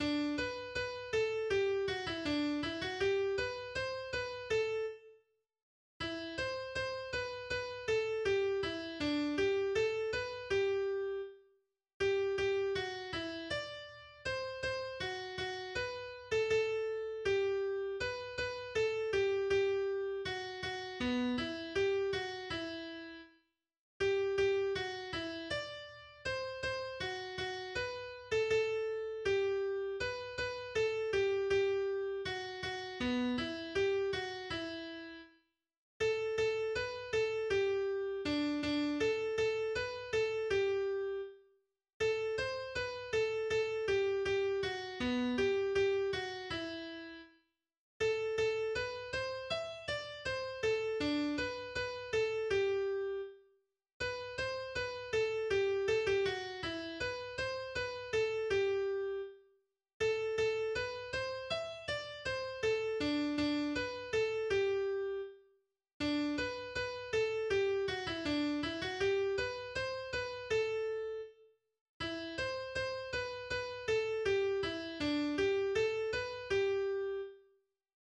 ist ein russisches Volkslied